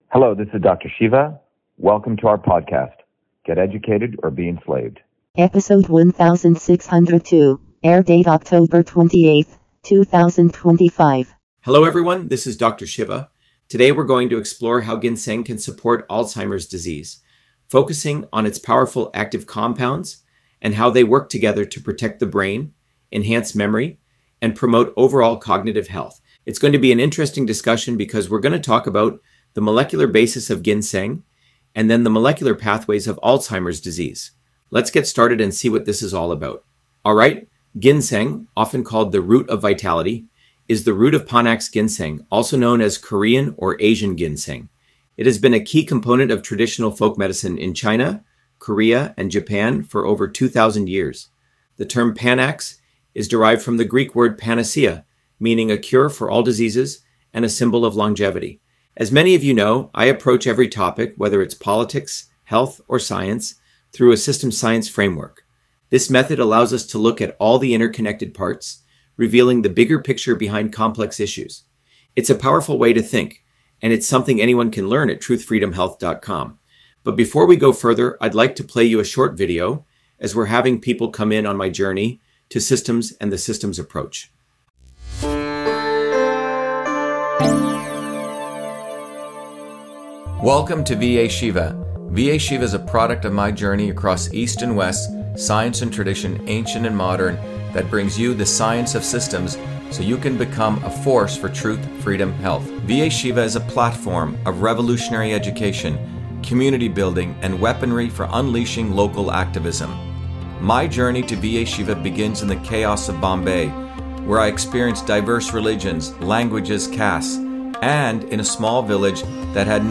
In this interview, Dr.SHIVA Ayyadurai, MIT PhD, Inventor of Email, Scientist, Engineer and Candidate for President, Talks about Ginseng on Alzheimer’s Disease: A Whole Systems Approach